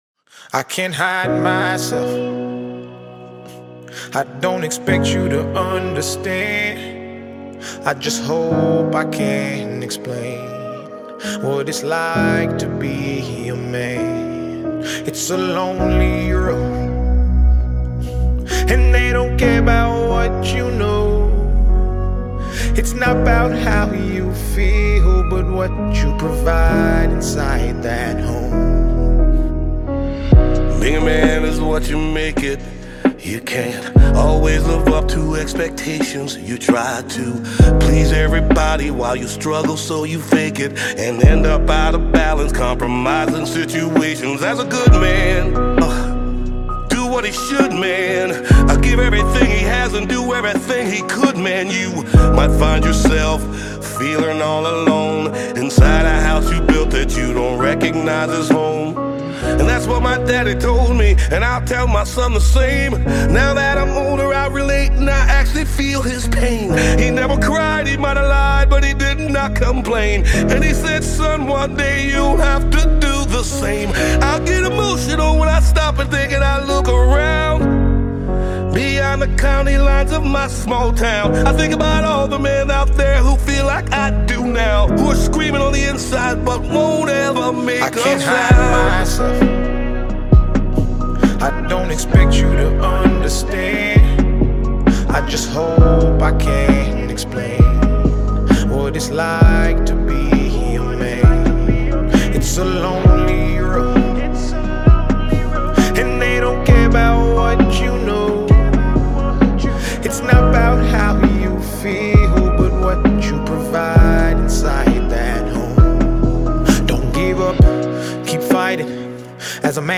Nigeria/American singer-songwriter